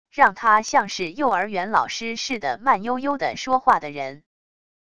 让他像是幼儿园老师似的慢悠悠的说话的人wav音频